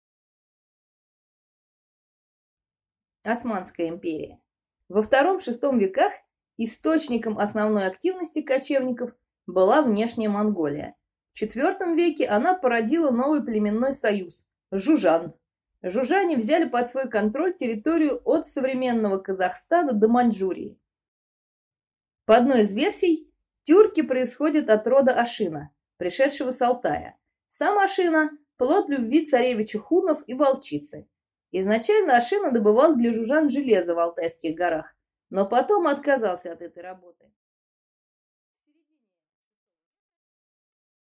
Аудиокнига Османы | Библиотека аудиокниг